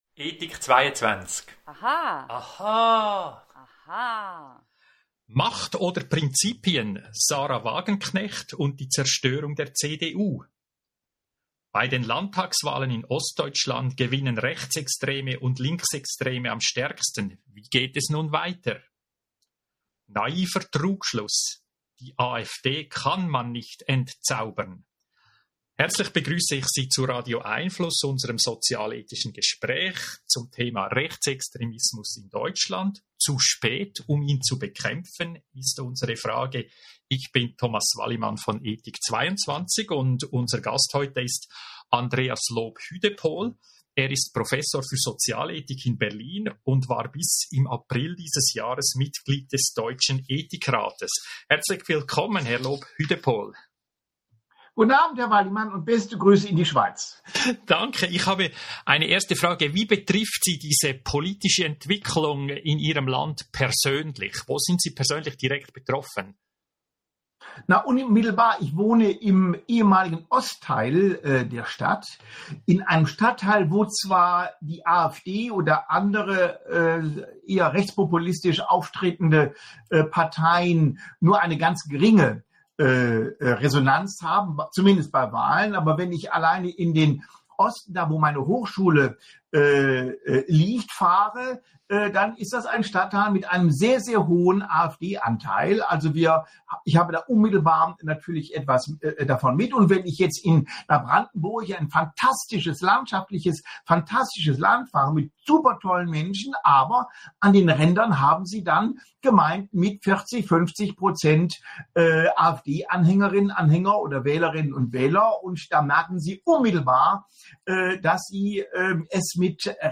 Radio🎙einFluss Audio-Gespräche informiert!